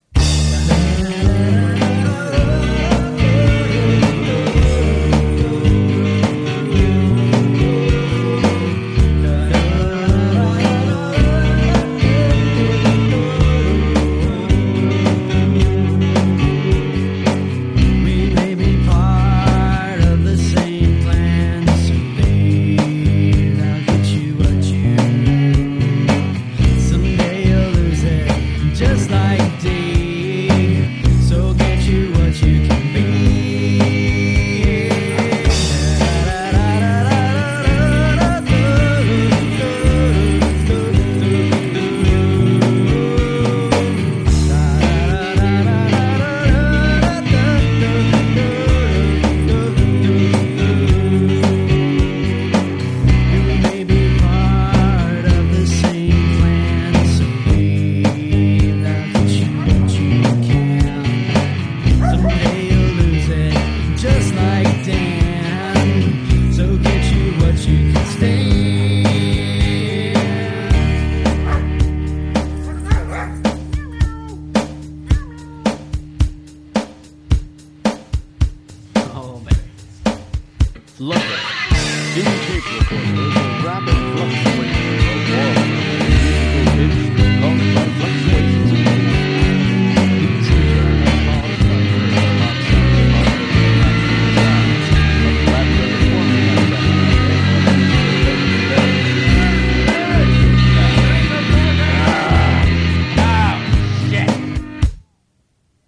Recorded in Cleveland